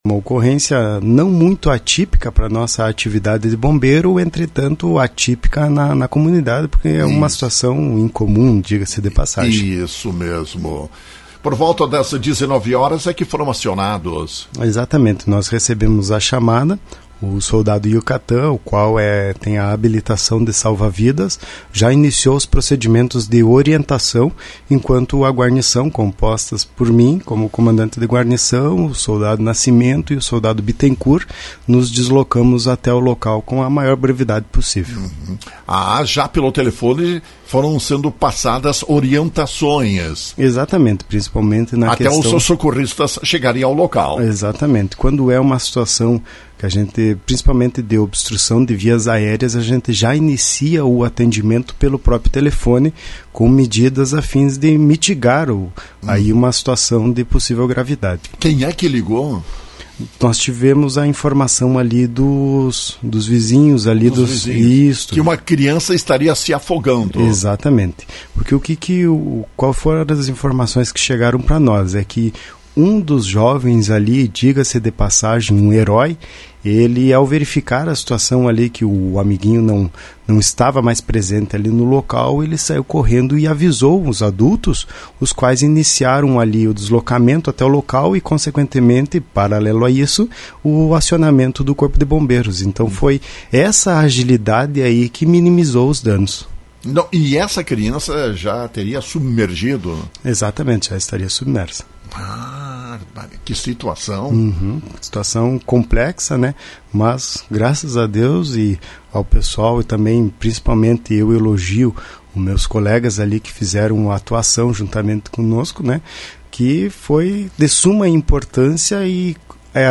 em entrevista à Rádio Lagoa FM